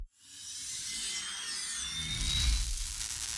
Weapon 16 Start (Laser).wav